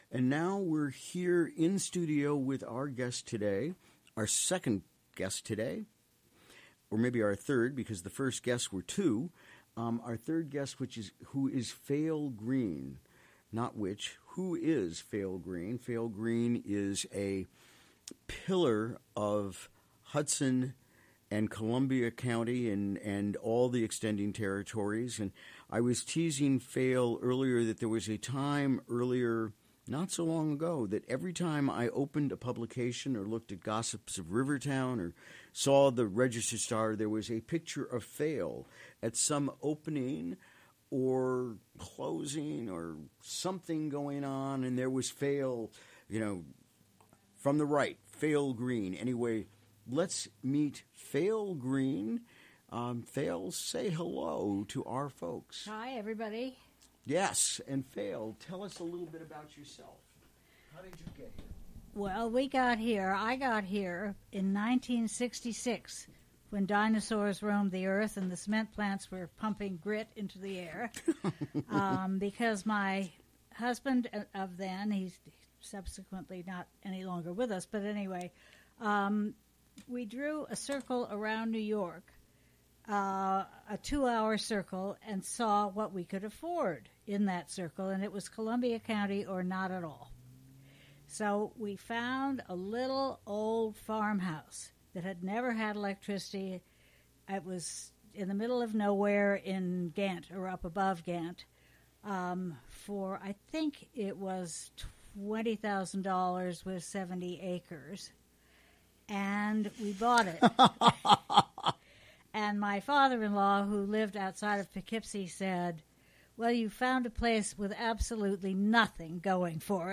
Recorded during the WGXC Morning Show on Wednesday, April 12.